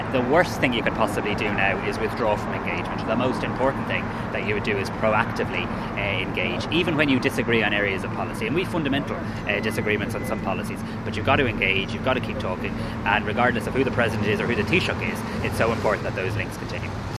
Speaking before Cabinet, Simon Harris said it’s crucial to engage with the US, no matter who’s in charge: